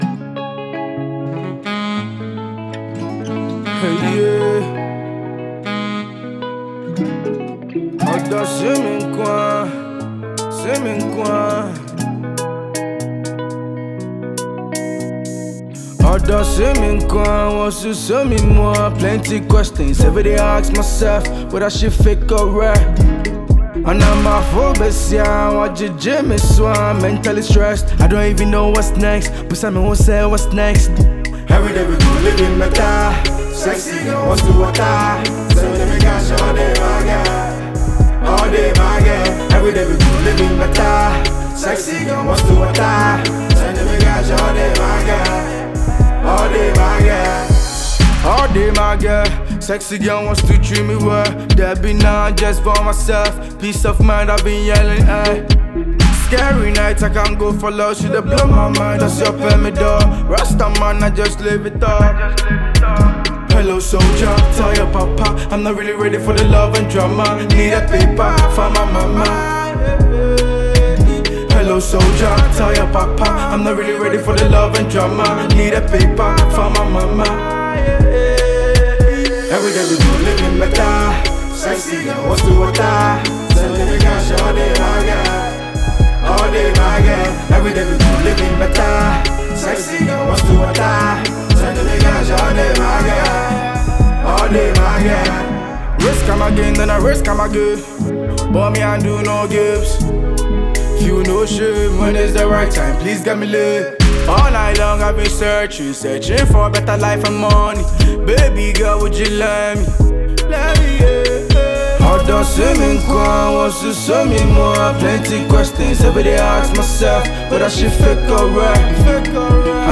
Afro rapper